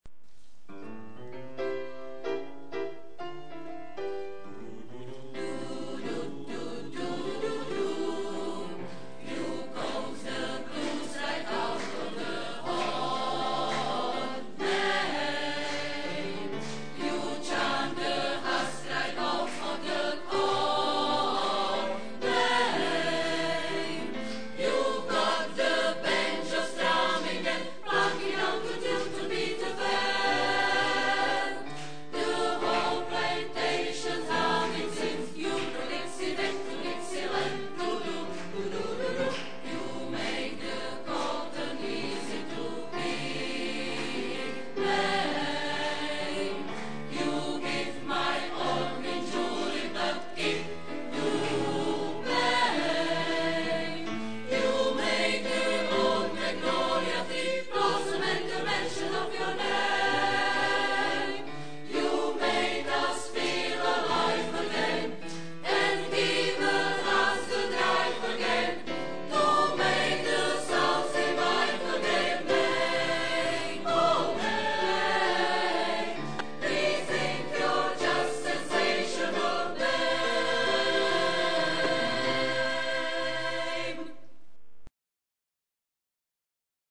Muzikálové melodie